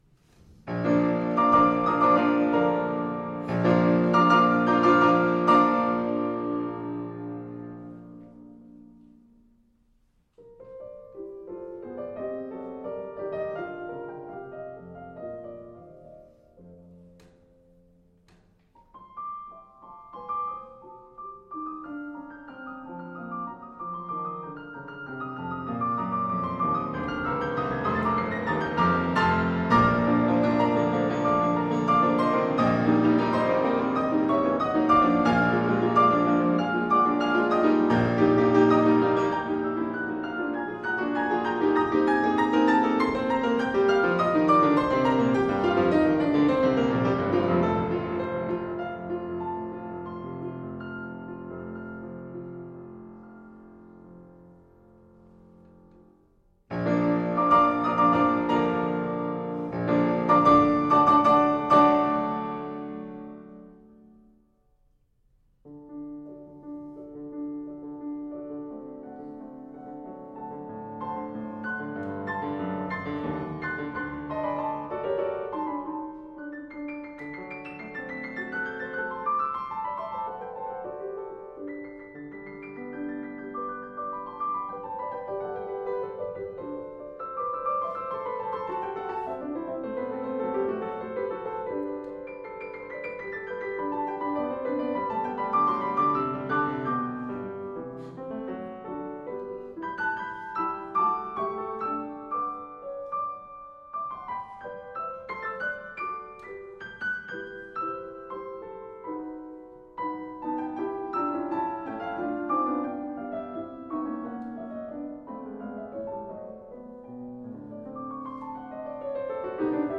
Piano
solo piano
Style: Classical
Audio: Boston - Isabella Stewart Gardner Museum
Audio: Jeremy Denk (piano)